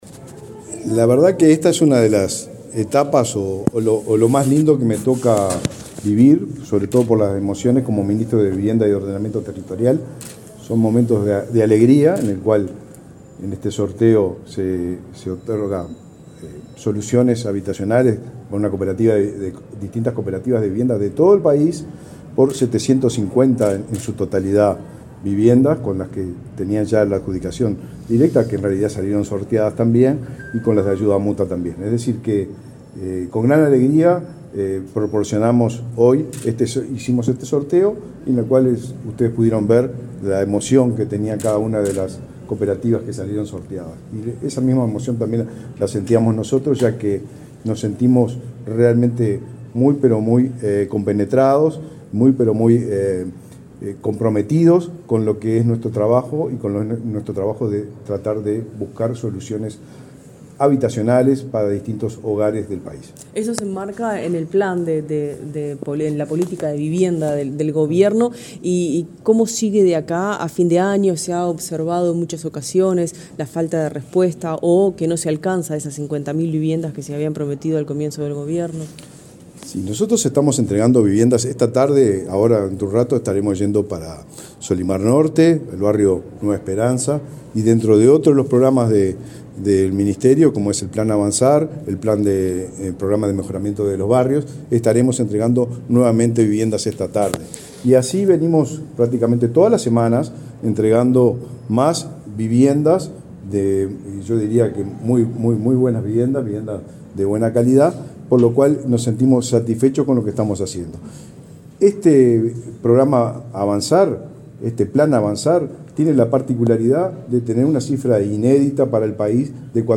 Declaraciones del ministro de Vivienda, Raúl Lozano
El ministro de Vivienda, Raúl Lozano, dialogó con la prensa, luego del sorteo de cupos para la construcción de viviendas cooperativas, realizado este